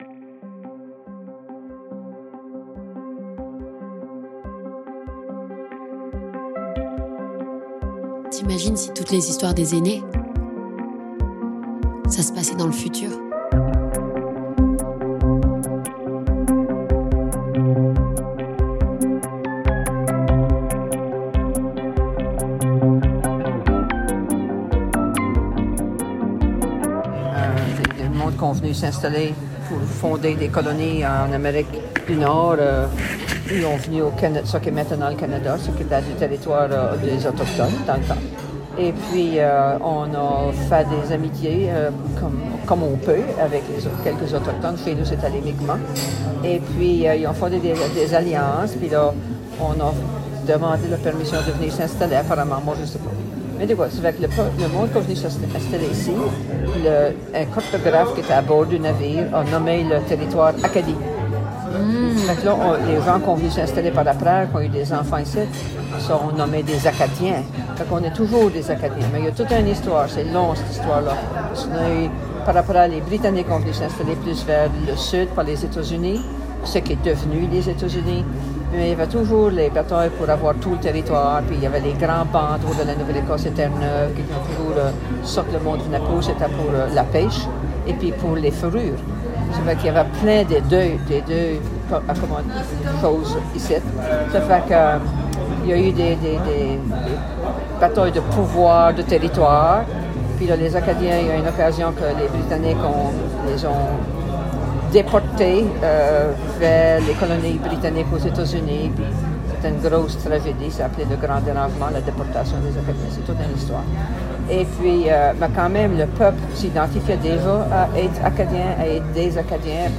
Nous sommes dans la brasserie du Caveau de Trois Pistoles en pleine effervescence de festival, bière à l'érable à la main on se raconte ...
Partager Type Entretien Société vendredi 20 décembre 2024 Lire Pause Télécharger ÉPISODE 5 - Histoire de la terre Acadienne !